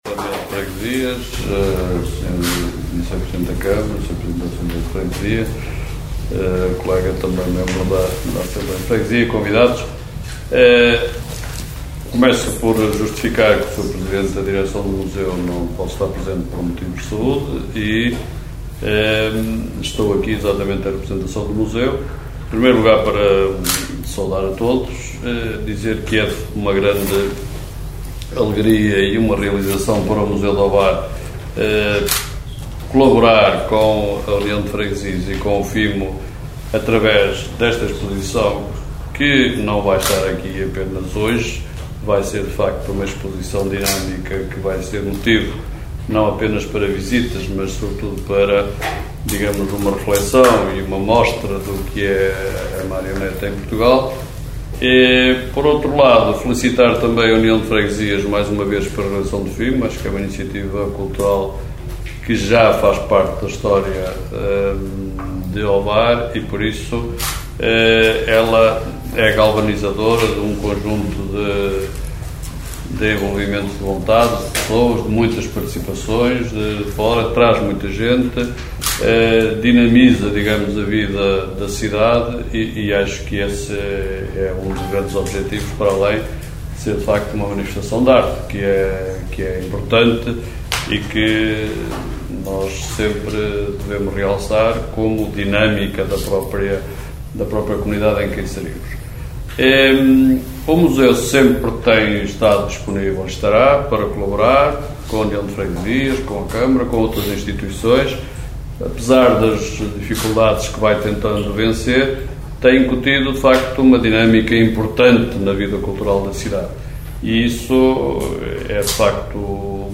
Algumas figuras direta ou indiretamente relacionadas com o festival tiveram a oportunidade de discursar.